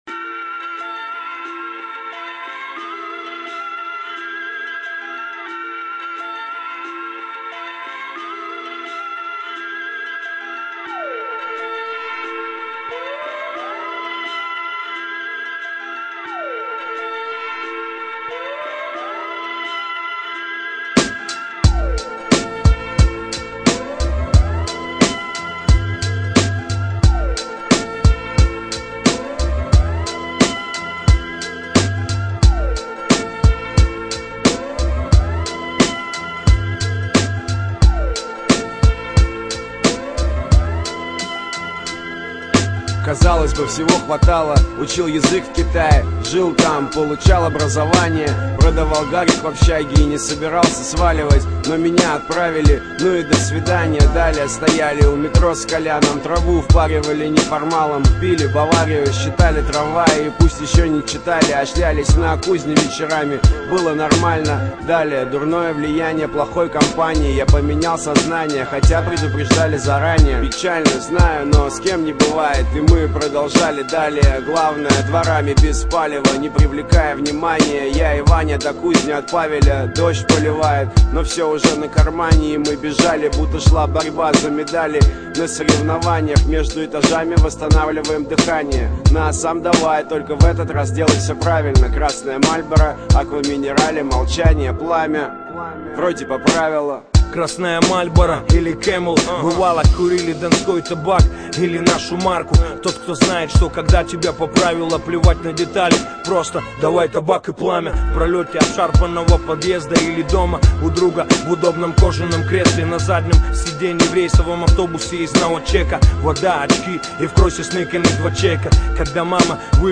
rap музыка